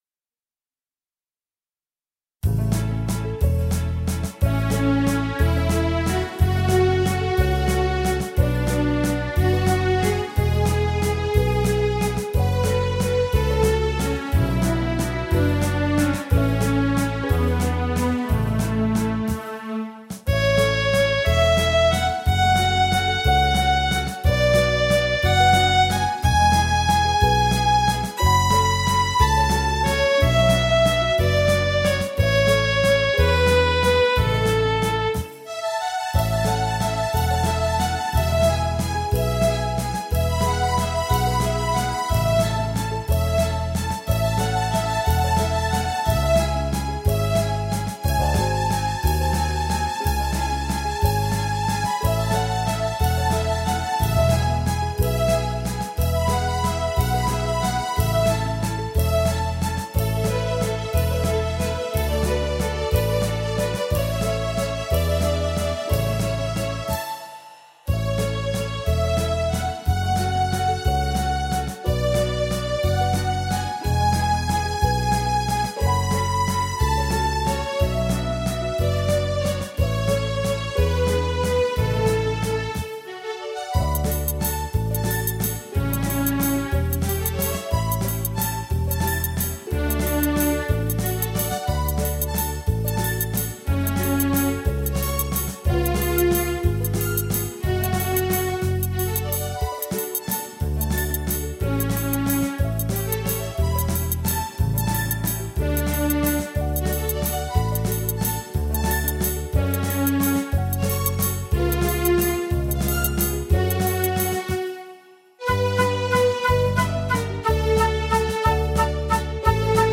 0110-电子琴名曲溜冰圆舞曲.mp3